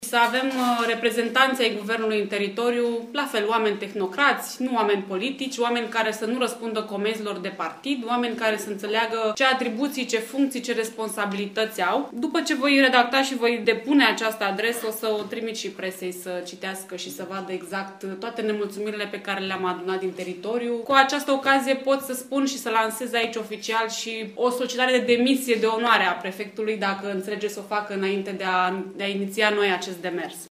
Co-preşedinţii PNL Caraş-Severin, Marcel Vela şi Valeria Schelean au declarat astăzi, în cadrul unei conferinţe de presă, la Reşiţa, că liberalii vor depune o cerere oficială pentru schimbarea actualului prefect, Nicolae Miu Ciobanu, pentru „modul dezastruos în care a gestionat problemele judeţului”.
Deputatul Valeria Scheleanu, co-preşedinte al PNL Caraş-Severin: